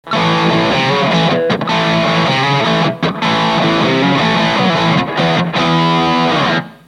Guitar Fender STRTOCASTER
Amplifier VOX AD30VT AC15
GAIN全開VOLUME全開
確かに「闘魂BOOSTER」の方がミドル強調されているようです。
きらびやかな感じが薄まってしまった気がします。